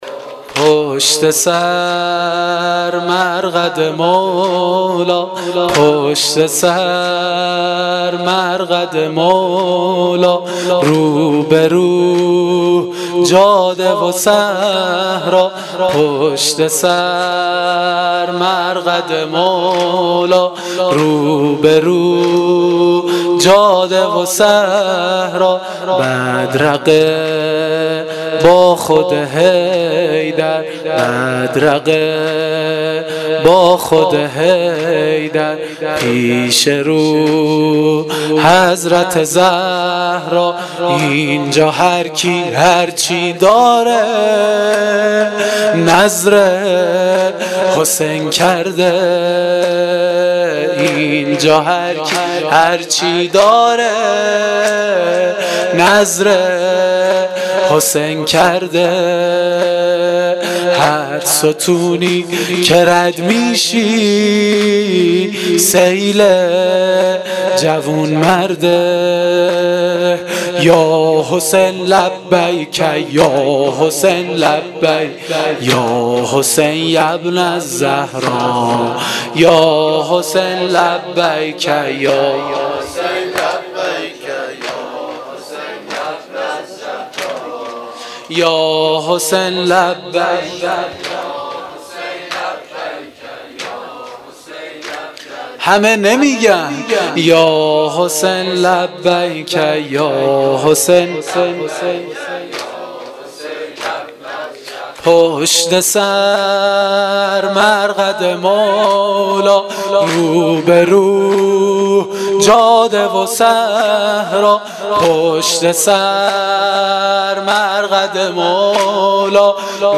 شب اربعین94 زمینه1.mp3
شب-اربعین94-زمینه1.mp3